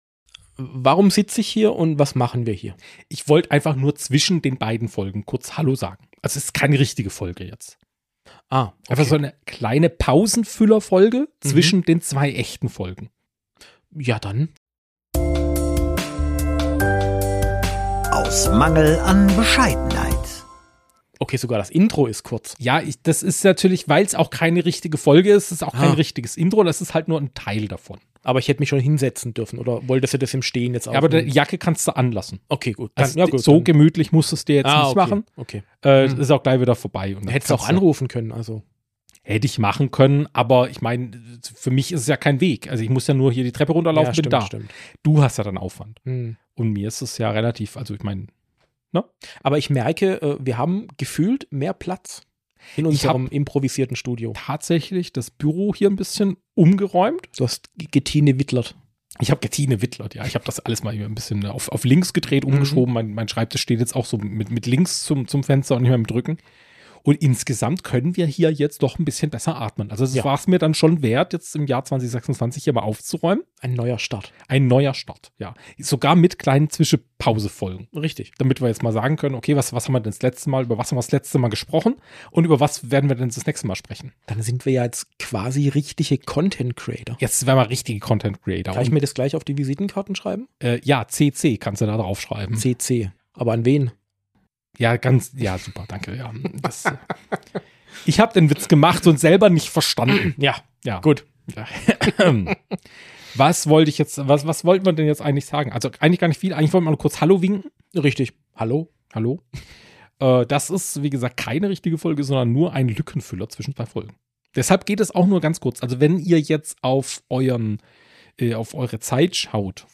In dieser Mini‑Folge testen wir, was passiert, wenn wir unsere eigene Idee aus der letzten Episode zu ernst nehmen und einfach in 1,3‑facher Geschwindigkeit miteinander reden.